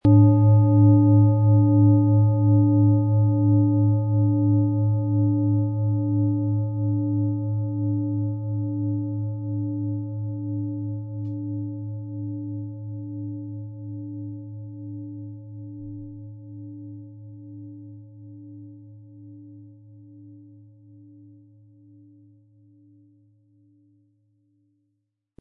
Planetenschale® Öffnet für Träume & Lebensmut haben mit Neptun & Mars, Ø 22 cm inkl. Klöppel
Es ist eine von Hand getriebene Klangschale, aus einer traditionellen Manufaktur.
Ein die Schale gut klingend lassender Schlegel liegt kostenfrei bei, er lässt die Planetenklangschale Neptun harmonisch und angenehm ertönen.
PlanetentöneNeptun & Mars & Biorhythmus Körper (Höchster Ton)
MaterialBronze